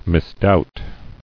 [mis·doubt]